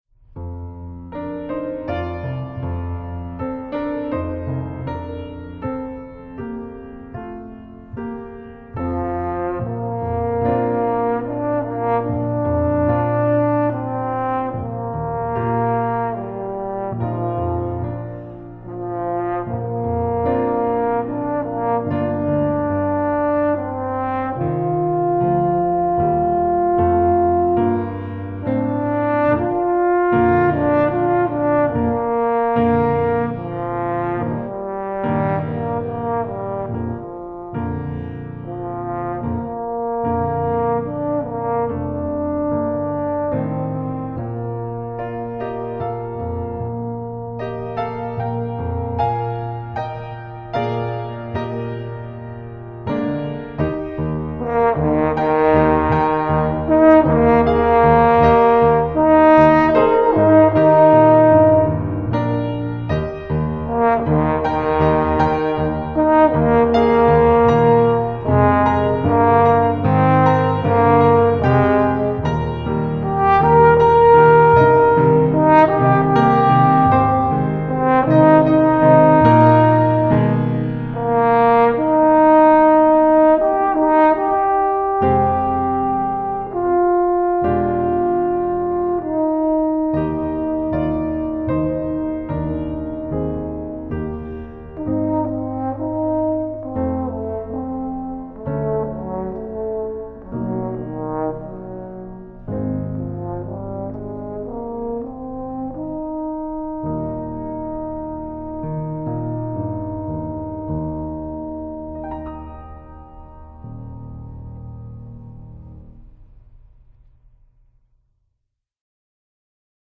Style: Contemporary Waltz
Instrumentation: French Horn and Piano